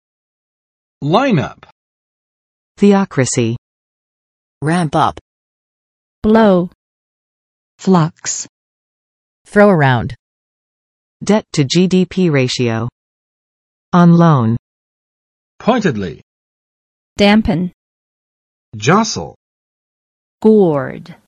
[ˋlaɪn͵ʌp] n. 阵容